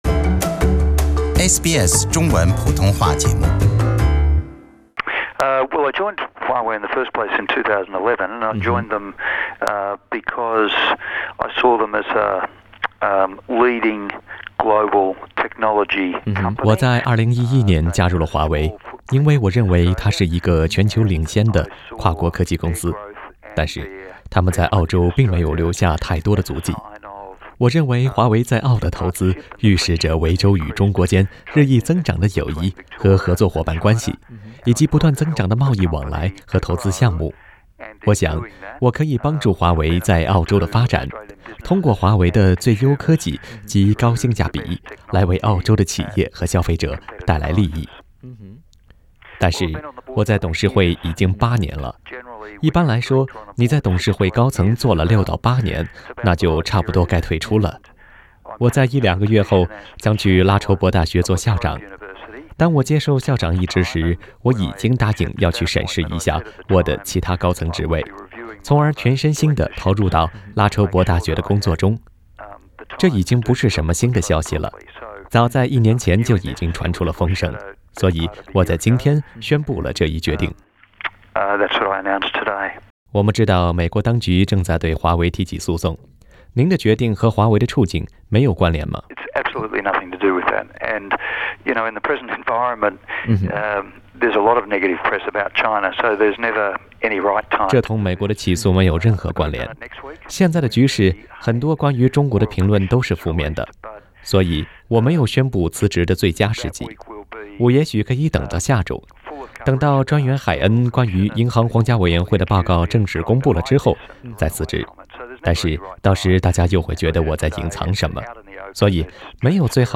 布倫比先生在接受本台記者寀訪時表示，他在此時請辭跟美國的訴訟案沒有關系。他先講述了自己在8年前加入華為的緣由。